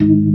FST HMND G#1.wav